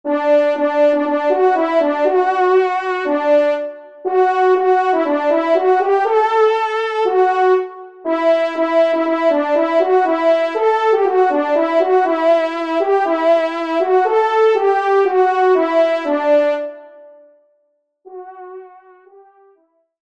Genre : Fantaisie Liturgique pour quatre trompes
Pupitre 1° Tromp